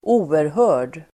Uttal: [²'o:erhö:r_d]